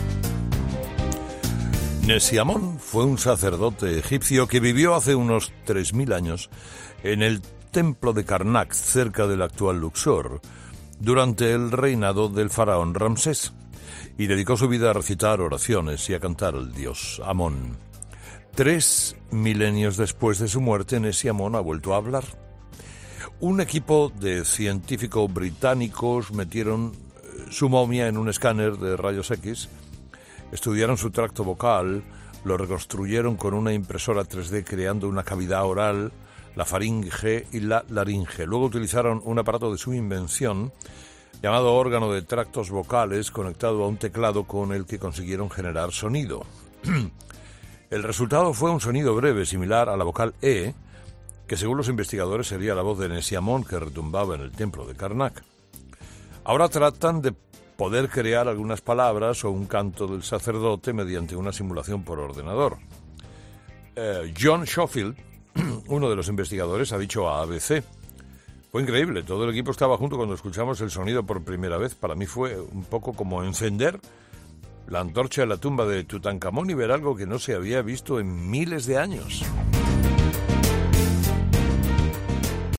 No te pierdas la historia que Herrera ha preparado para ti este viernes